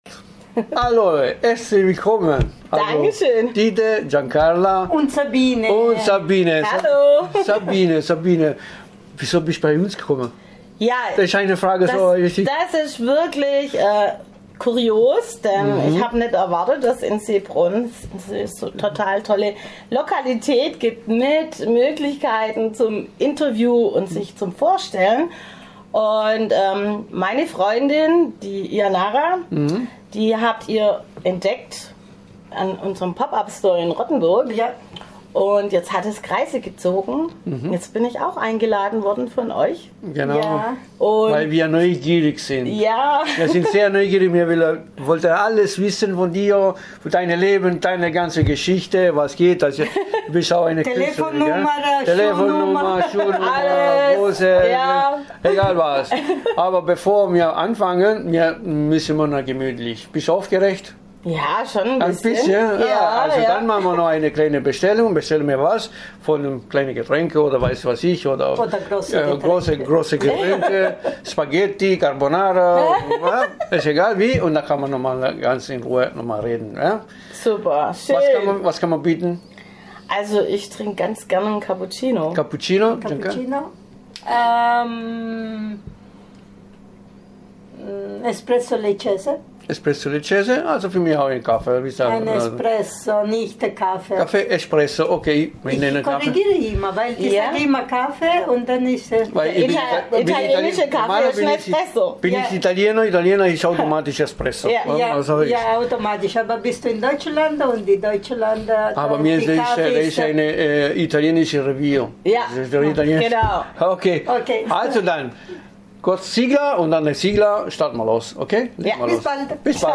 ICH LADE EUCH HERZLICH EIN, DIESES INTERVIEW, DAS HIER IN DER BESCHREIBUNG GETEILT WIRD, ANZUHÖREN, UM ALLES ÜBER IHRE KREATIVE HANDWERKSKUNST ZU ERFAHREN!